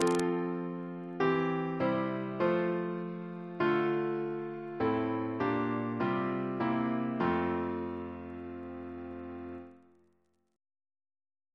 Single chant in F Composer: Arthur H. Brown (1830-1926) Reference psalters: H1940: 643